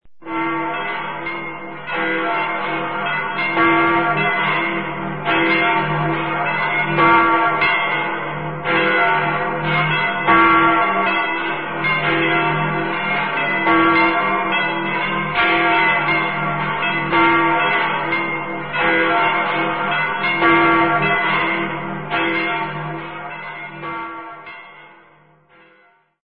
descargar sonido mp3 campanario
campanariocampanasiglesia.mp3